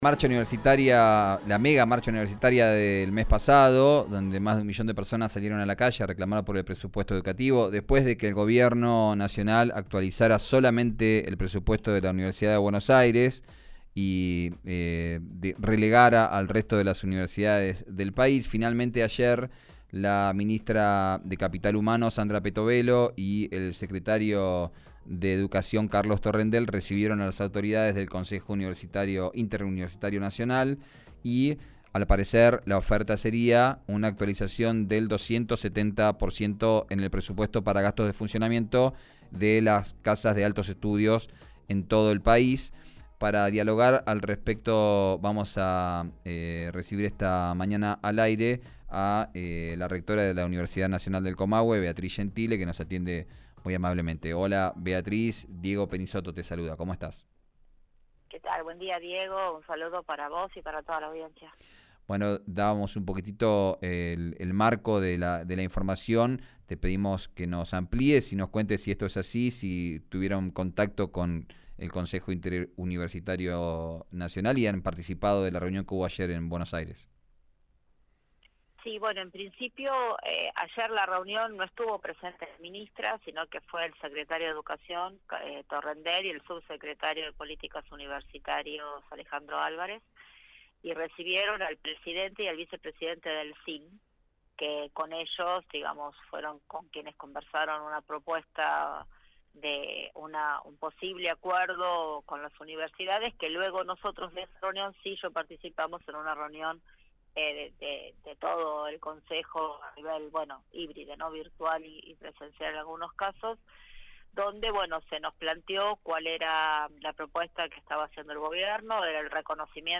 En una entrevista reciente con RÍO NEGRO RADIO, la rectora de la Universidad Nacional del Comahue (UNCo), Beatriz Gentile, abordó los detalles de una reunión clave para el futuro de las universidades nacionales.